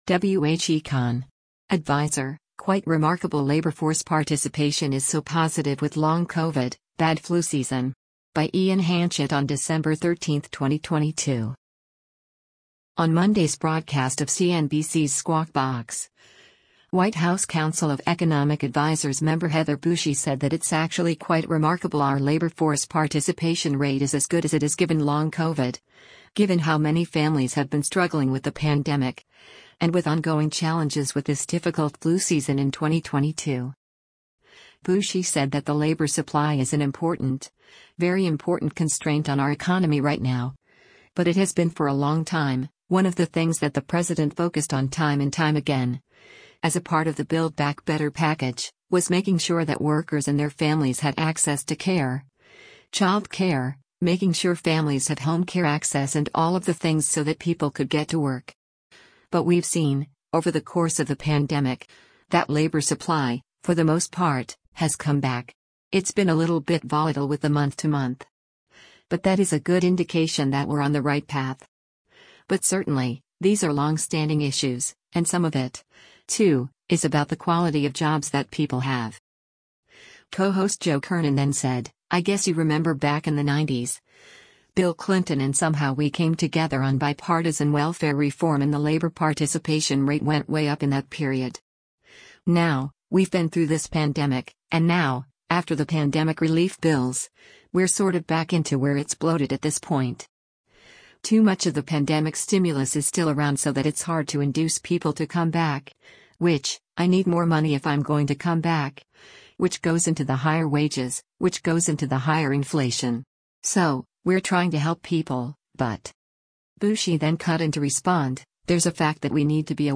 On Monday’s broadcast of CNBC’s “Squawk Box,” White House Council of Economic Advisers member Heather Boushey said that “it’s actually quite remarkable” our labor force participation rate is as good as it is “given long COVID, given how many families have been struggling with the pandemic, and with ongoing challenges with this difficult flu season” in 2022.